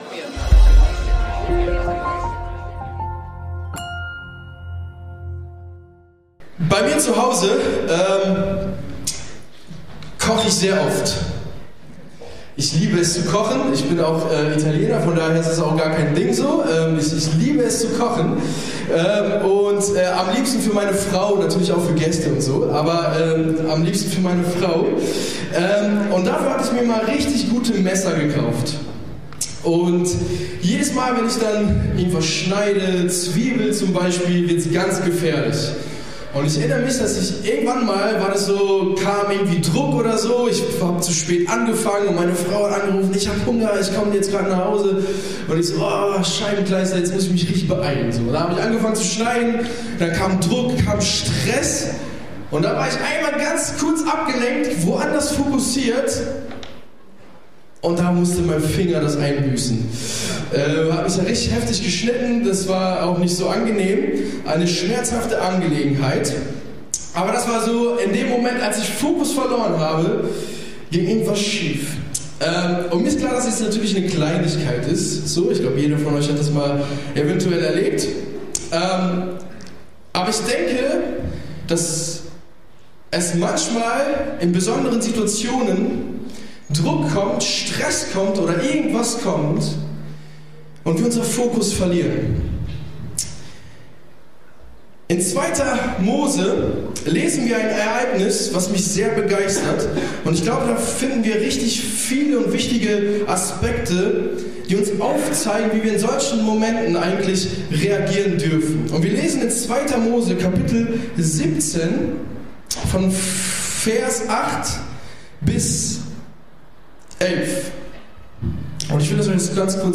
Predigt vom 24.04.22 ~ Predigten der LUKAS GEMEINDE Podcast